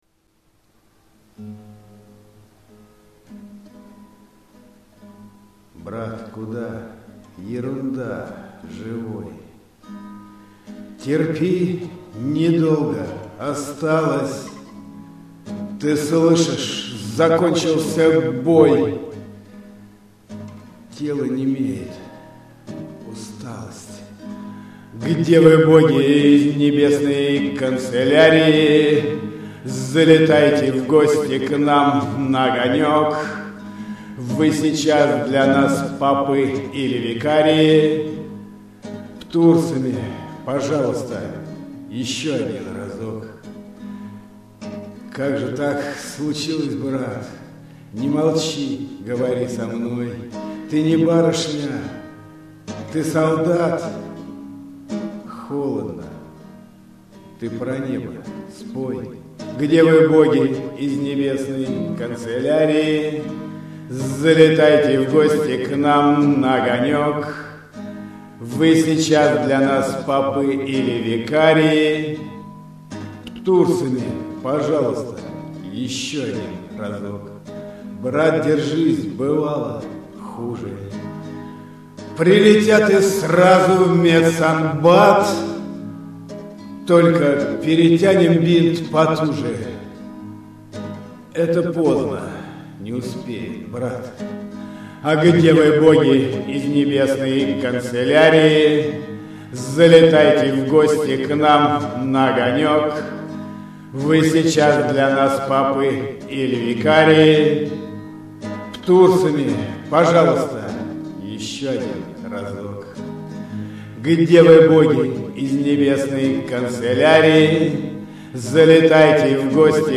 Музыкальный хостинг: /Шансон